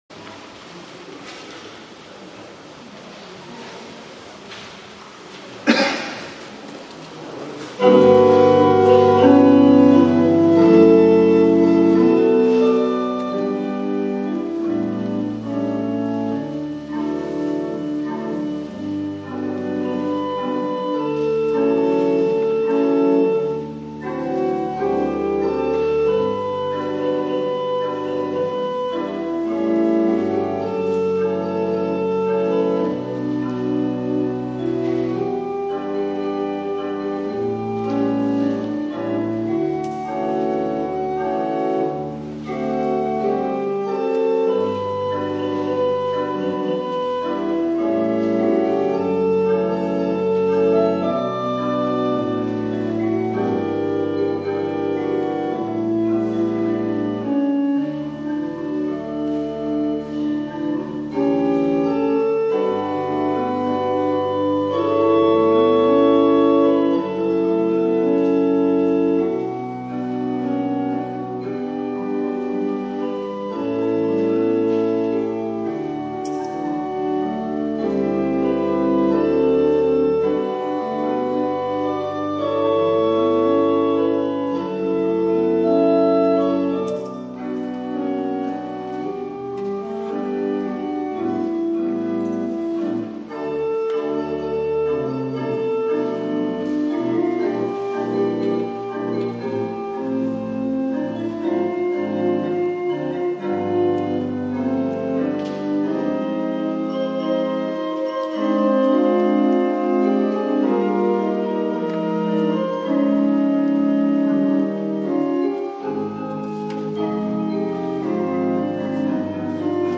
2025年6月29日(日)広島流川教会・関西学院をおぼえての礼拝